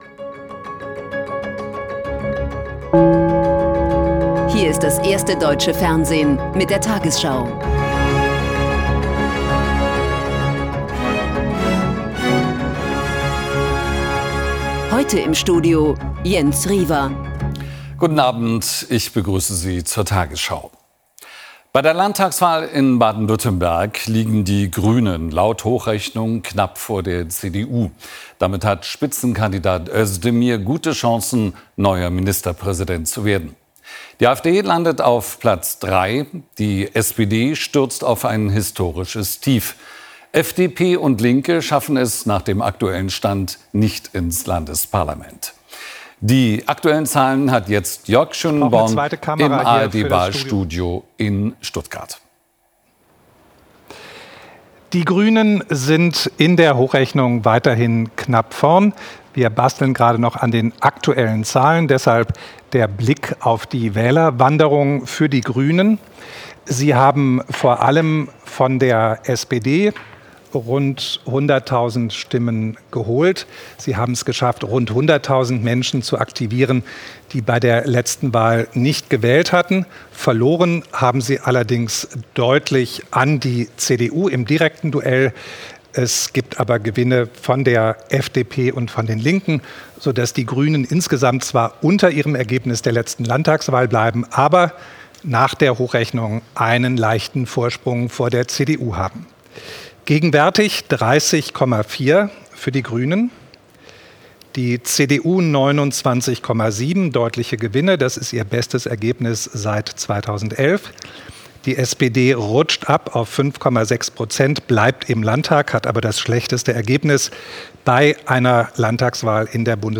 tagesschau 20:00 Uhr, 08.03.2026 ~ tagesschau: Die 20 Uhr Nachrichten (Audio) Podcast